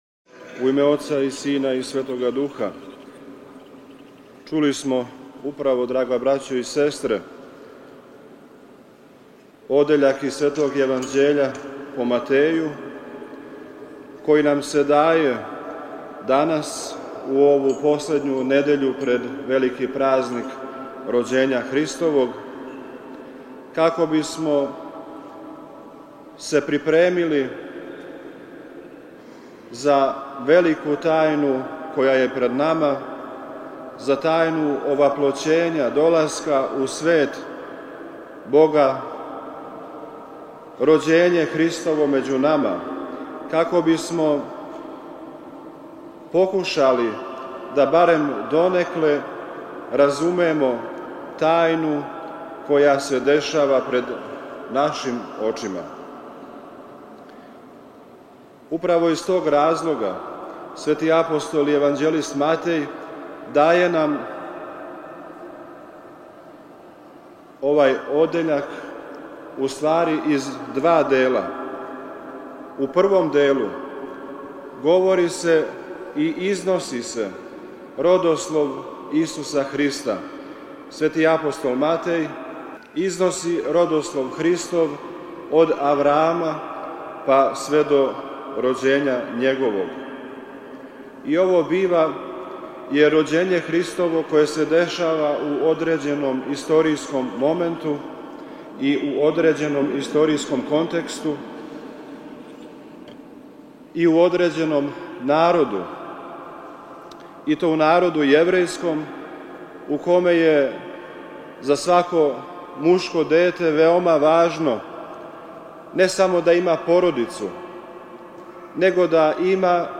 У молитвеном присуству Његове Светости Патријарха српског г. Порфирија, у недељу 29. по Духовима, 1. јануара 2023. године, свету архијерејску Литургију је у Спомен-храму светога Саве на Врачару предводио Његово Преосвештенство викарни Епископ ремезијански г. Стефан.
Звучни запис беседе
Празничну беседу је по читању јеванђељског зачала произнео Преосвећени викарни Епископ марчански г. Сава, који је са свештенством и ђаконима АЕМ саслуживао на овом молитвеном сабрању.